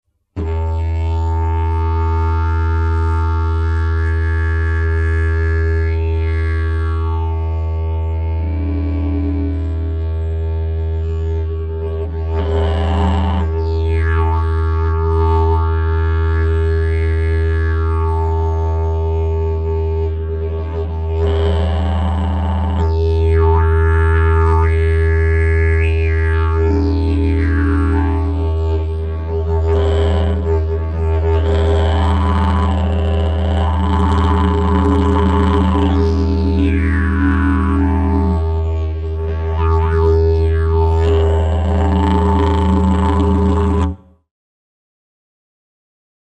9.3 Rolling
Sample n°14 contiene: rolling semplice e modulato modificando il volume del cavo orale.